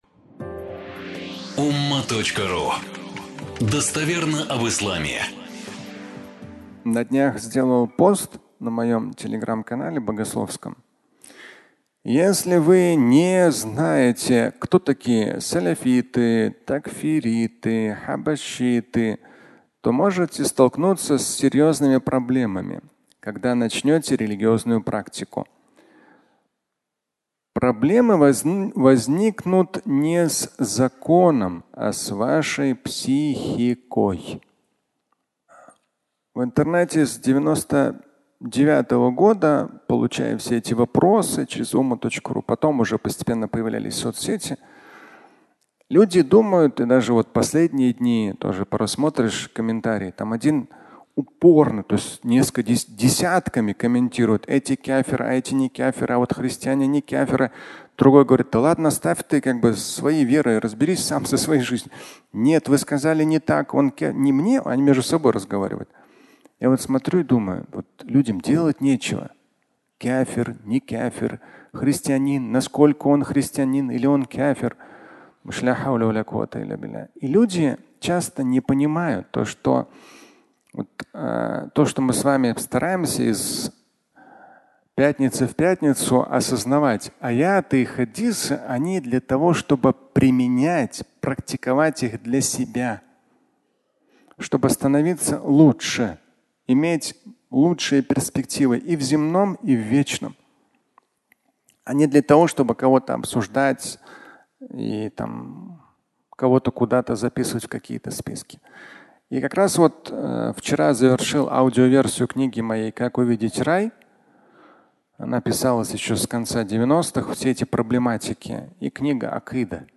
Акыда и Рай (аудиолекция)
Фрагмент пятничной лекции